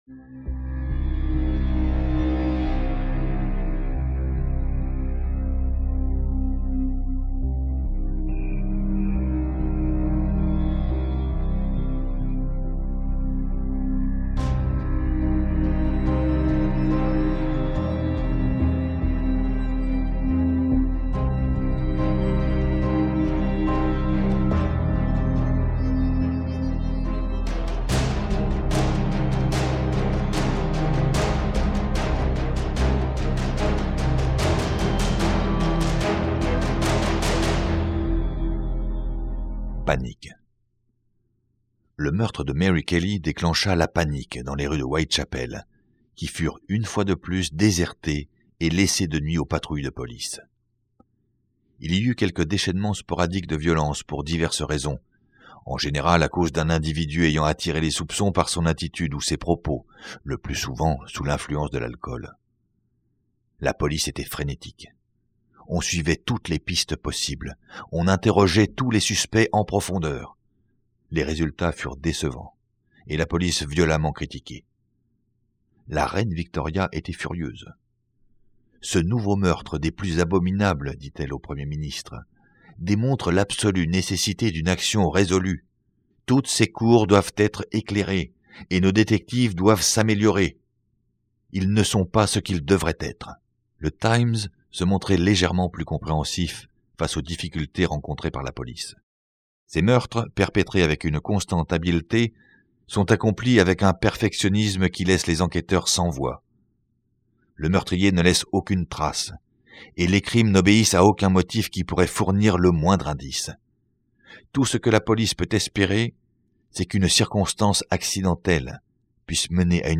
Livre audio